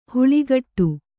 ♪ huḷigaṭṭu